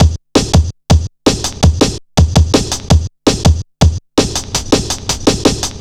Index of /90_sSampleCDs/Zero-G - Total Drum Bass/Drumloops - 2/track 40 (165bpm)